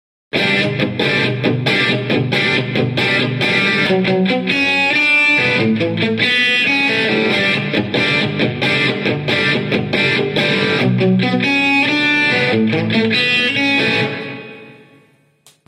Texas special an Neck (Fender Strat) schrill, leichtes Brummen -> wiring?
Habe hier mal Tonaufnahmen zum Vergleich mit meiner Ibanez gemacht...
Bei der Fender , gerade beim Spielen der einzelnen Töne, kratzig und schrill.